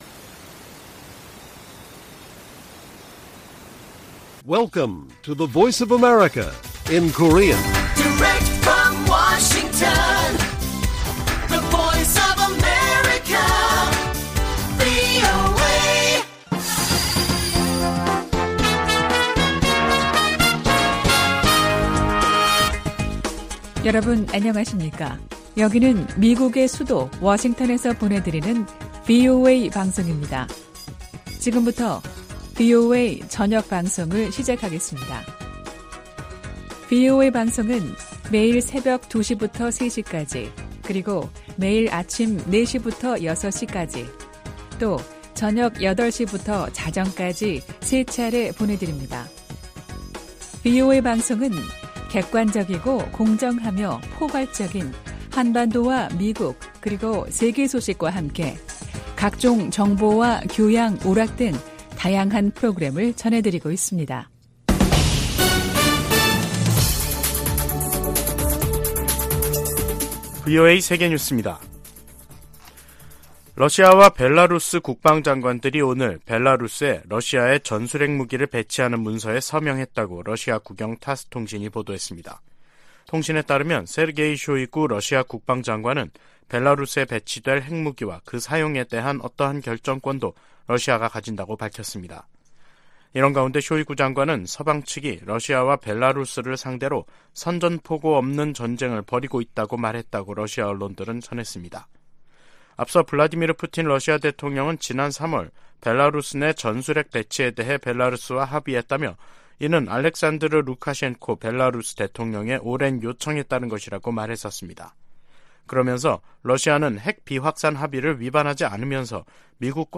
VOA 한국어 간판 뉴스 프로그램 '뉴스 투데이', 2023년 5월 25일 1부 방송입니다. 조 바이든 미국 대통령이 최근 미한일 정상회담에서 한일 정상의 관계 개선 노력을 높이 평가했다고 백악관 고위 관리가 밝혔습니다. 미군과 한국군이 25일부터 한반도 휴전선 인근 지역에서 역대 최대 규모 화력격멸훈련에 돌입했습니다.